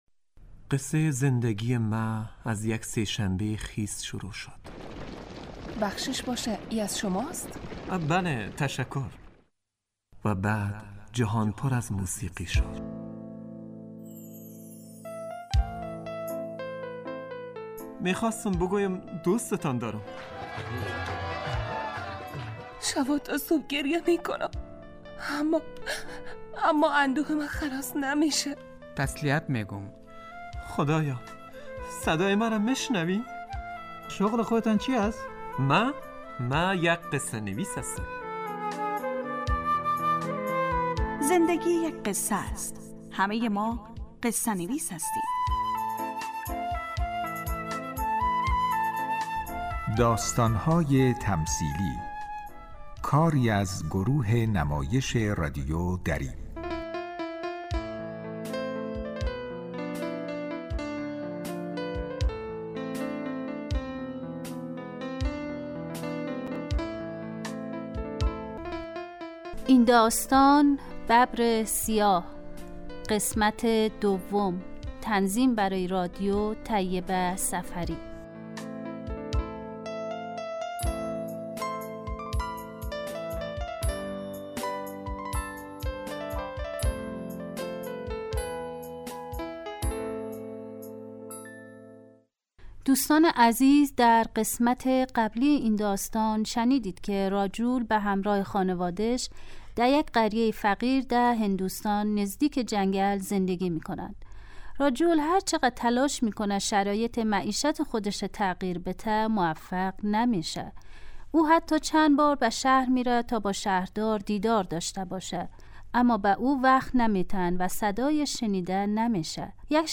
داستانهای تمثیلی نمایش 15 دقیقه ای هست که از شنبه تا پنج شنبه ساعت 03:20 عصربه وقت افغانستان پخش می شود.